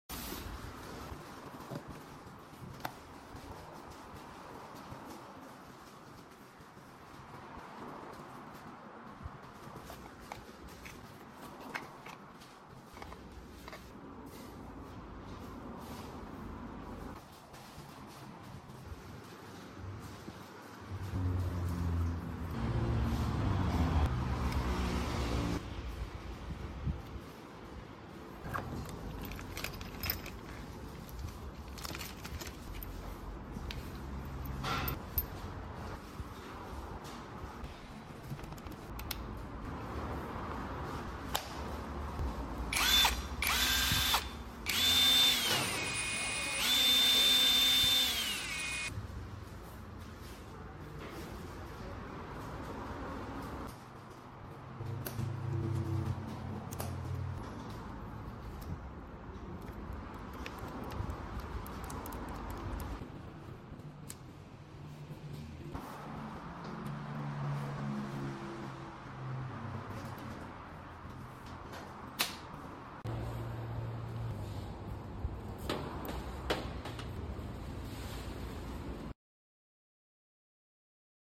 WADFOW Brushless Motor Impact Driver sound effects free download